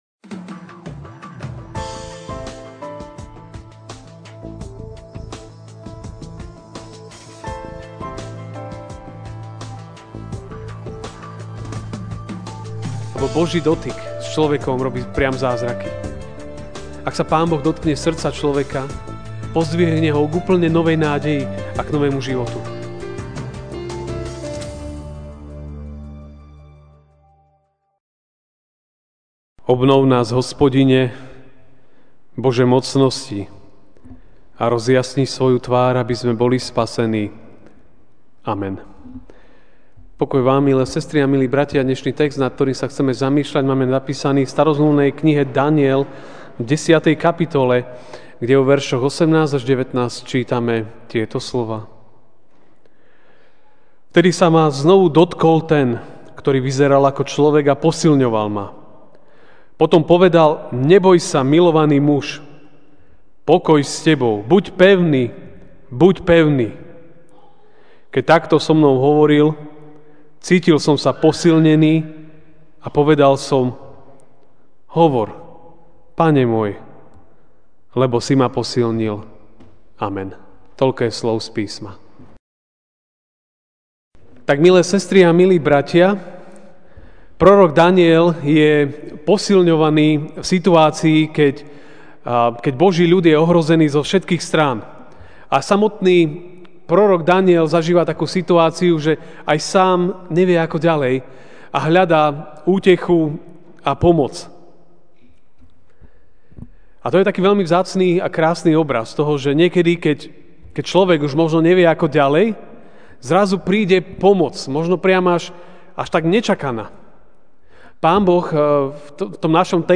Večerná kázeň: Boží dotyk (Daniel 10, 18-19) Vtedy sa ma znova dotkol ten, kto vyzeral ako človek, a posilňoval ma.